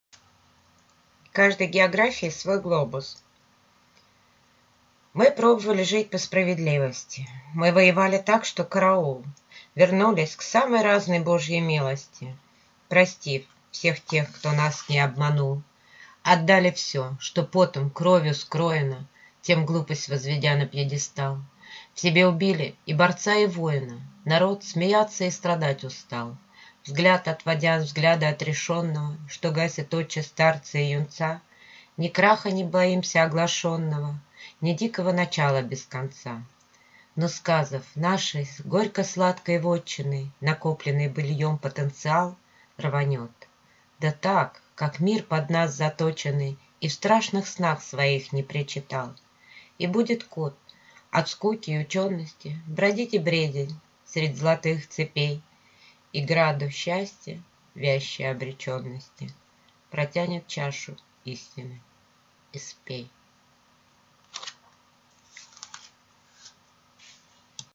Слушать авторское прочтение: «Мы пробовали жить по справедливости»